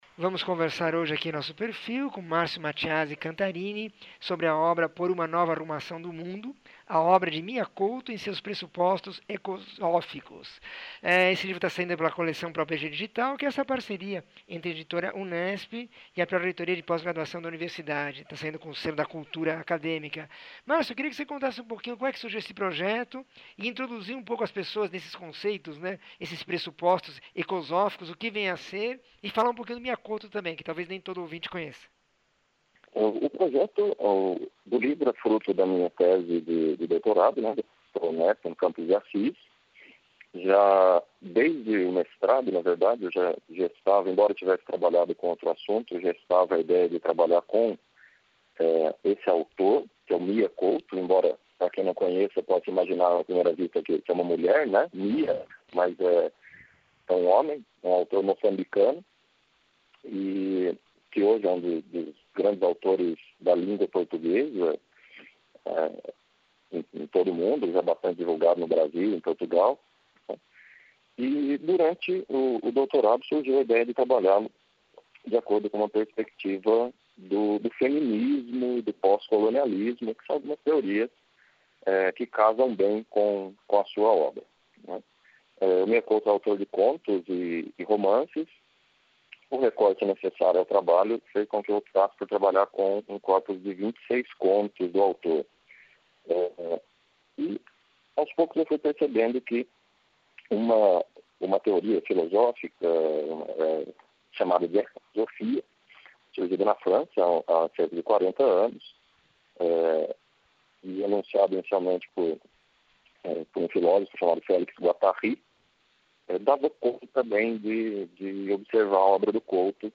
entrevista 1740